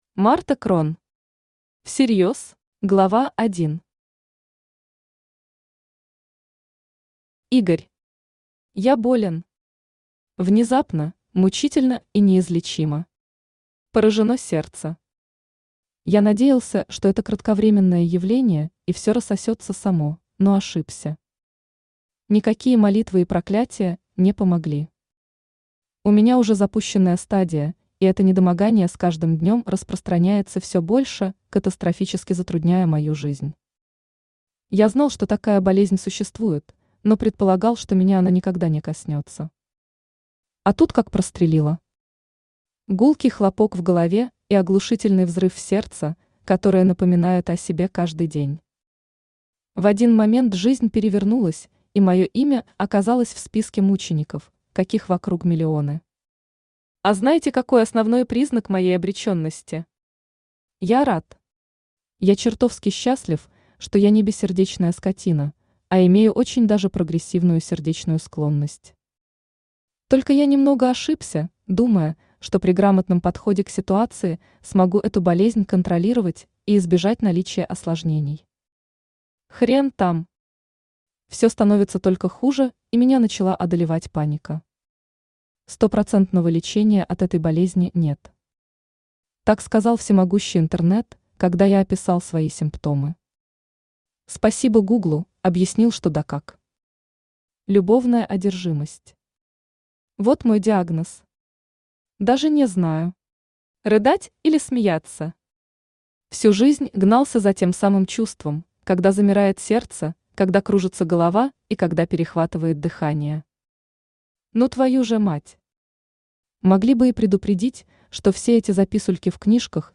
Аудиокнига Всерьёз | Библиотека аудиокниг
Aудиокнига Всерьёз Автор Марта Крон Читает аудиокнигу Авточтец ЛитРес.